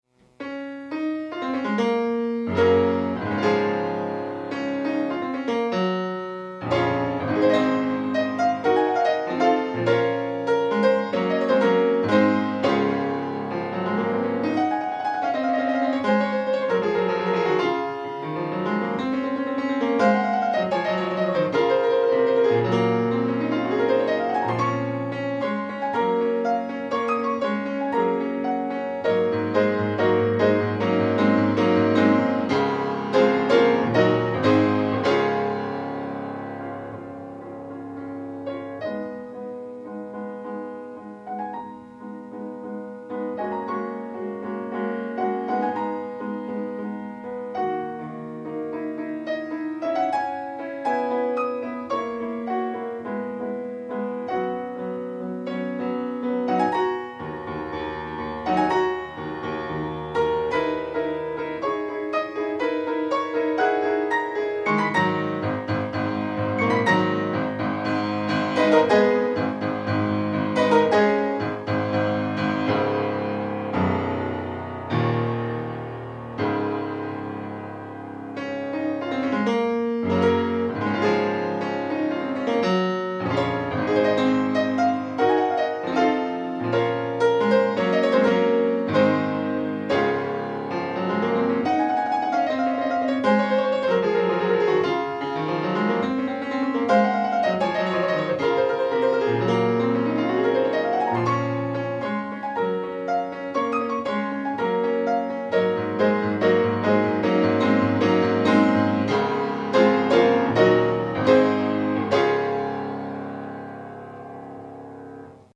Klavierstücke
gespielt auf einem Feurich 197